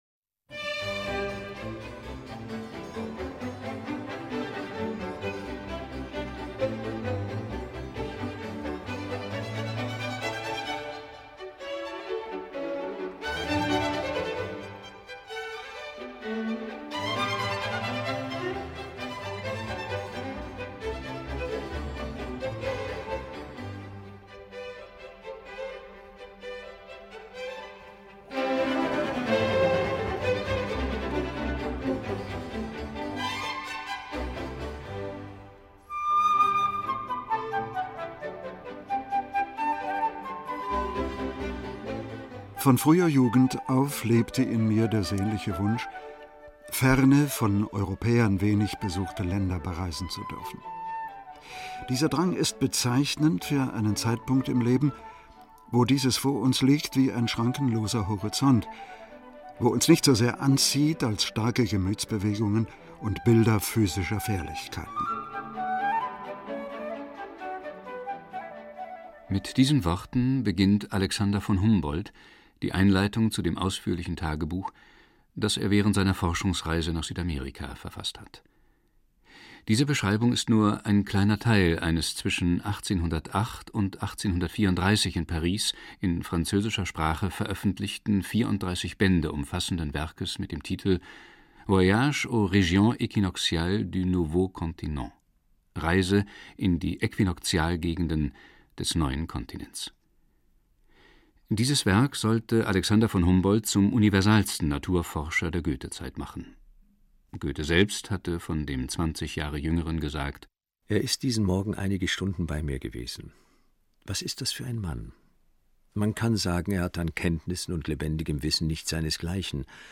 Audio des Hörstücks